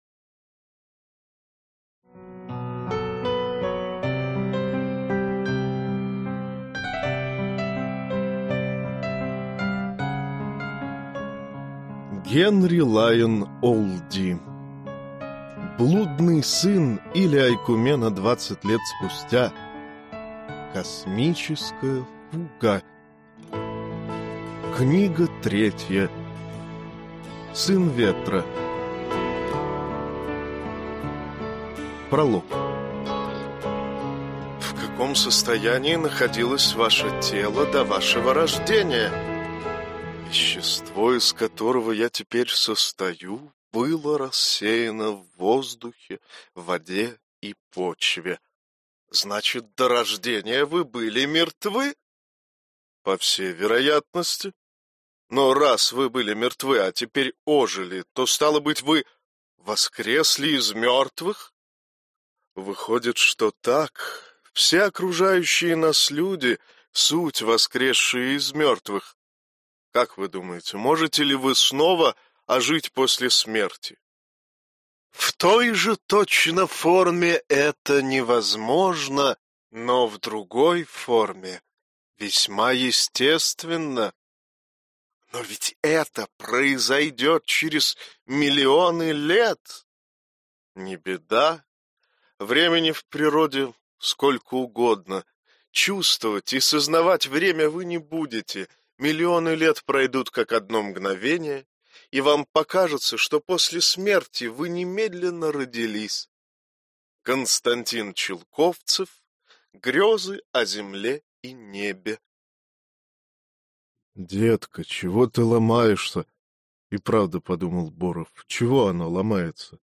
Аудиокнига Сын Ветра - купить, скачать и слушать онлайн | КнигоПоиск